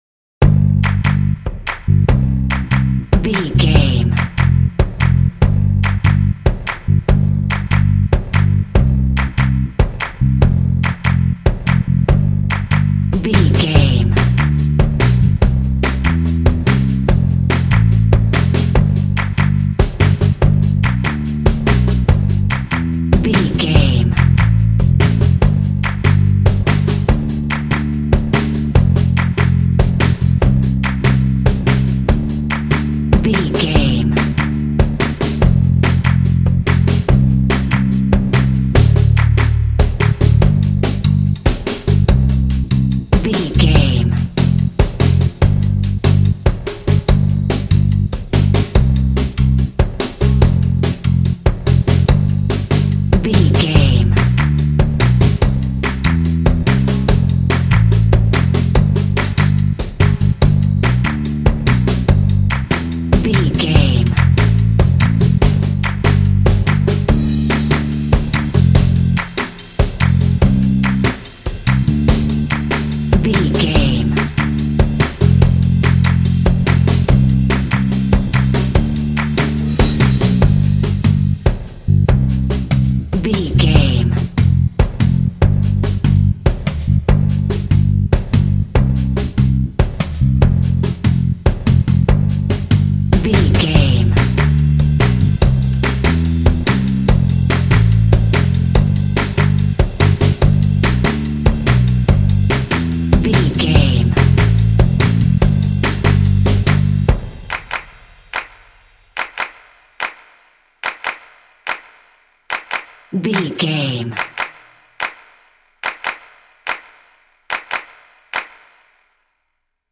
This cute pop underscore is great for kids and family games.
Ionian/Major
bright
happy
uplifting
optimistic
joyful
bass guitar
percussion
drums
pop
contemporary underscore